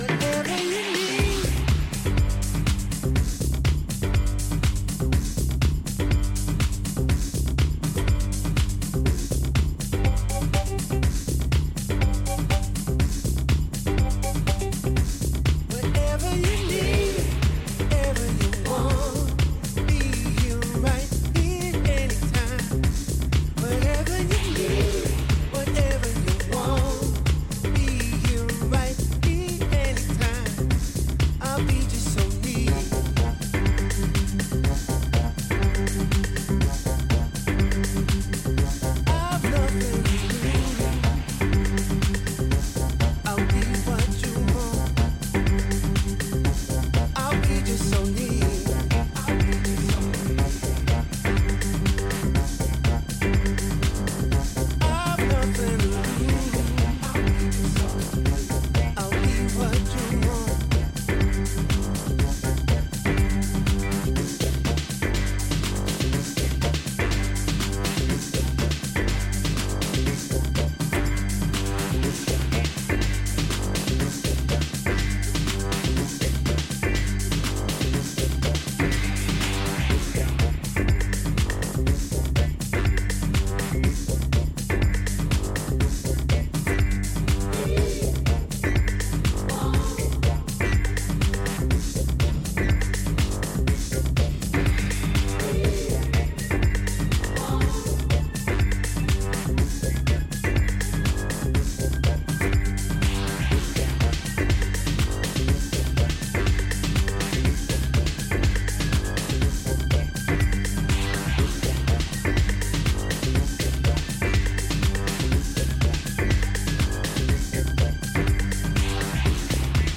Vocal Remix